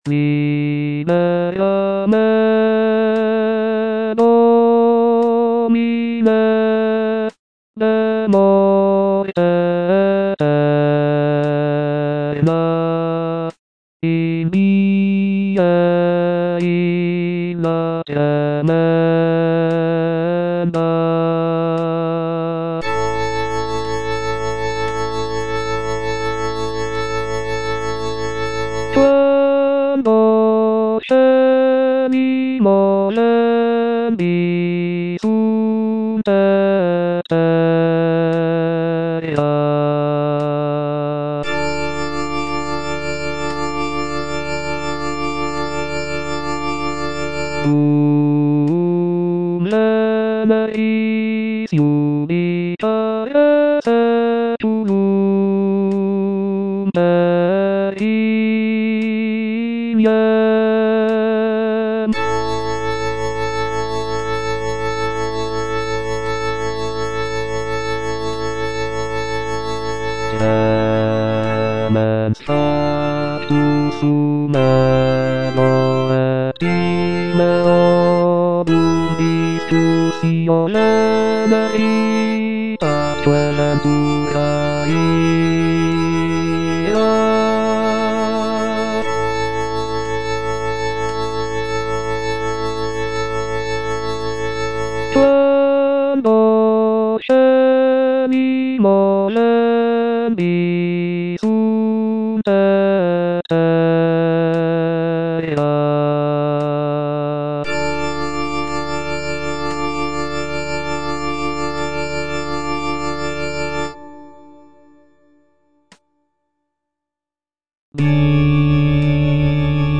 F. VON SUPPÈ - MISSA PRO DEFUNCTIS/REQUIEM Libera me (bass I) (Voice with metronome) Ads stop: auto-stop